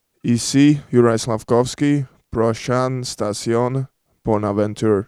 Les joueurs des Canadiens ont prêté leurs voix à la Société de transport de Montréal (STM) pour annoncer les arrêts sur la ligne orange à proximité du Centre Bell.